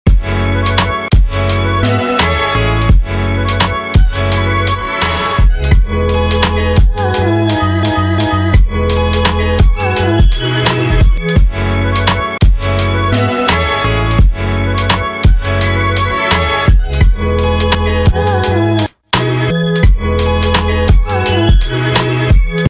Real Floaty Type Beat Honestly Sound Effects Free Download